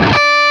LEAD D 4 LP.wav